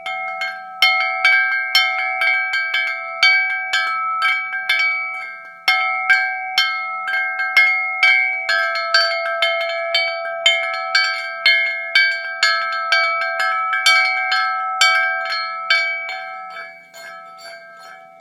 Zvonkohra kovová s lístkem 10,1 x 10,1 x 52,5 cm
Minimalistická zvonkohra, v luxusním tmavě hnědém provedení, okouzlí krásným detailem struktur